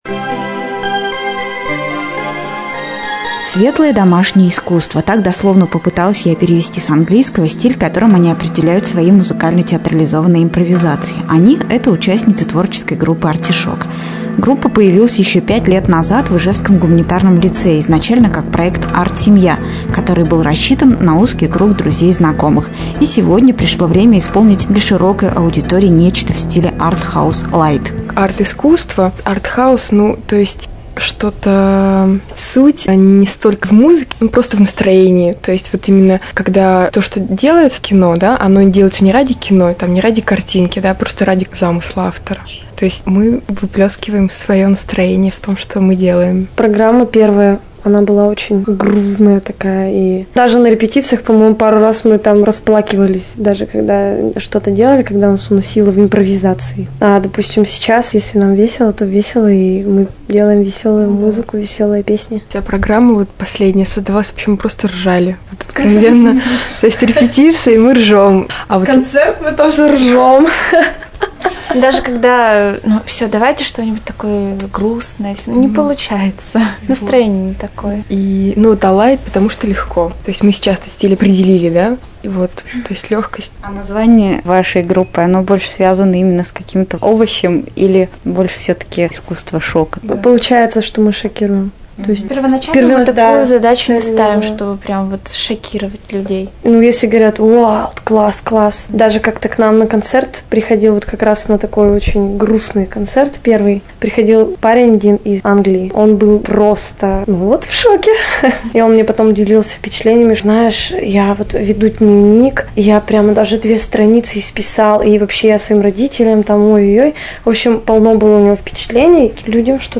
интервью "о нас" на радио, август 2006
interviewradio.mp3